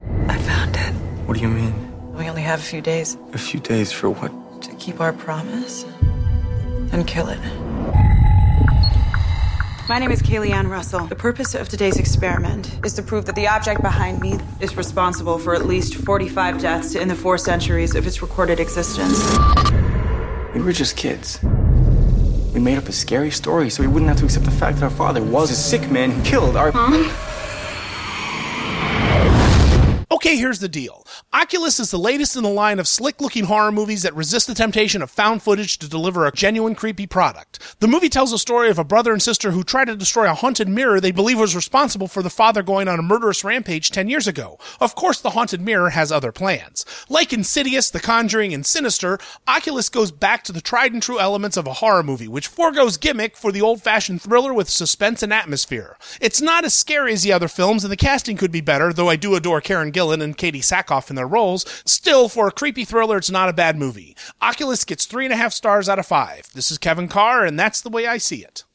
‘Oculus’ Movie Review